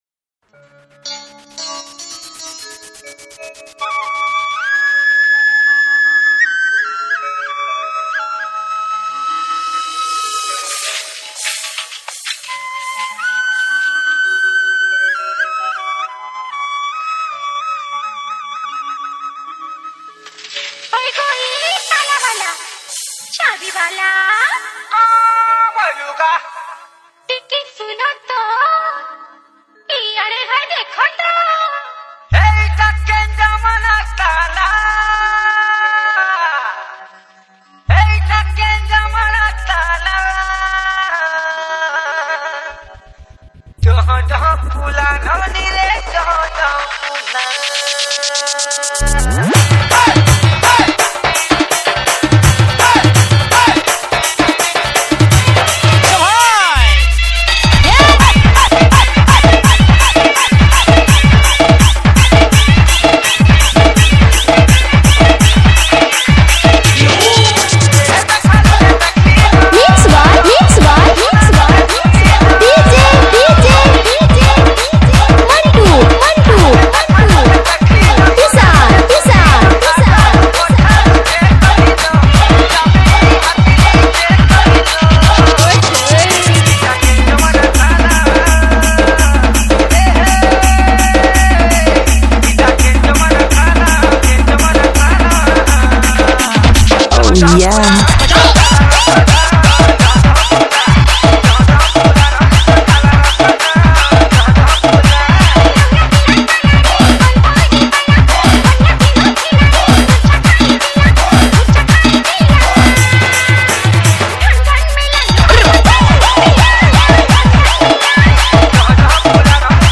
SAMBALPURI ROMANTIC DJ REMIX